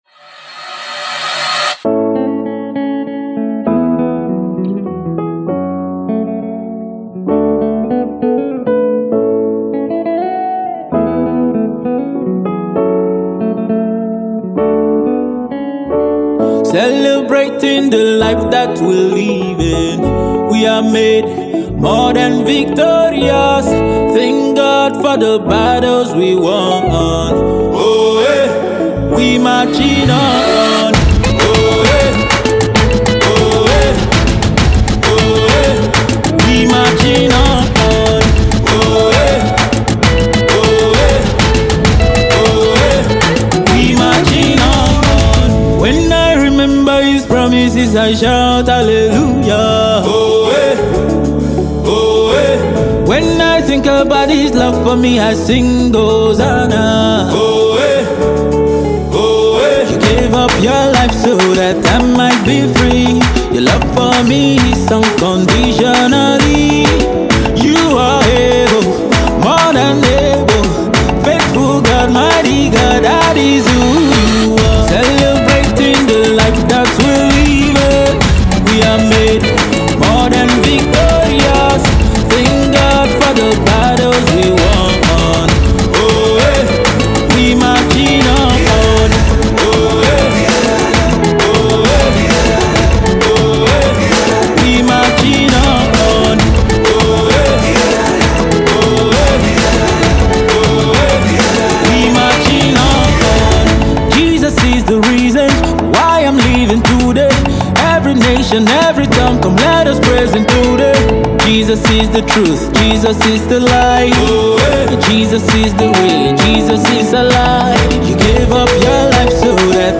This is a song of rejoicing and celebration